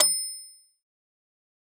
Roland.Juno.D _ Limited Edition _ GM2 SFX Kit _ 02.wav